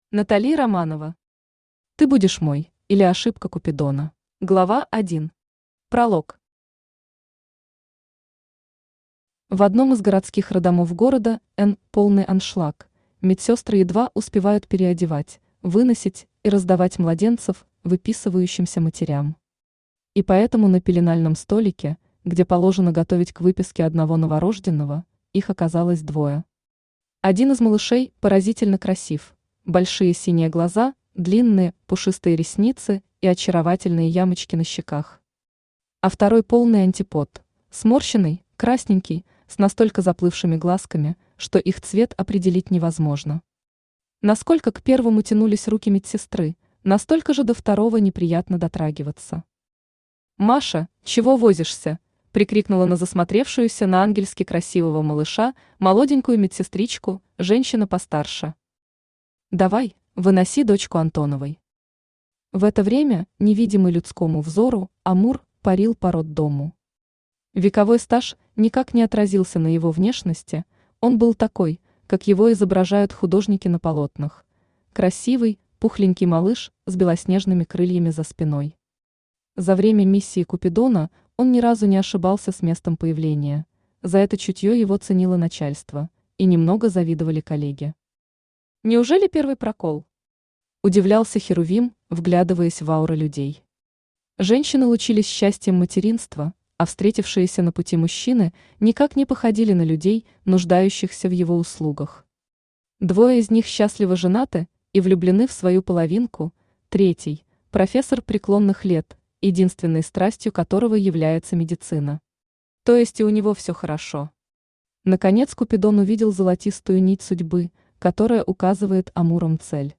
Аудиокнига Ты будешь мой, или Ошибка купидона | Библиотека аудиокниг
Aудиокнига Ты будешь мой, или Ошибка купидона Автор Натали Романова Читает аудиокнигу Авточтец ЛитРес.